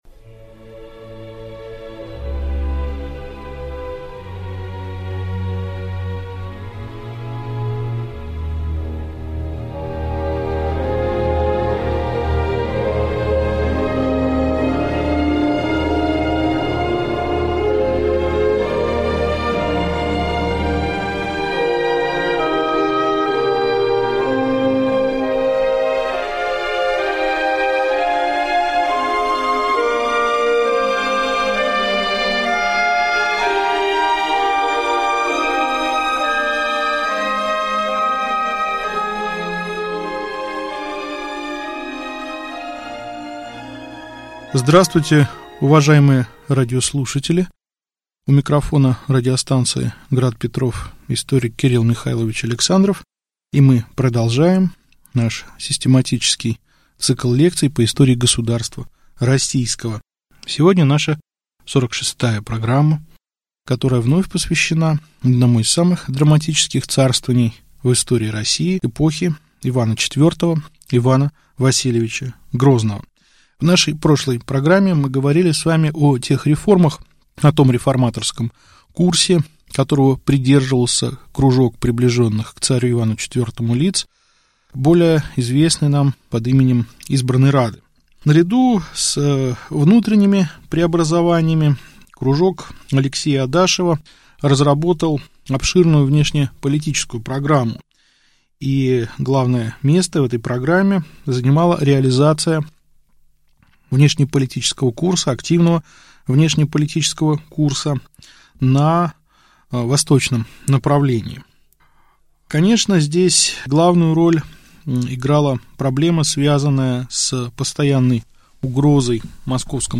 Аудиокнига Лекция 46. Причины падения Избранной рады | Библиотека аудиокниг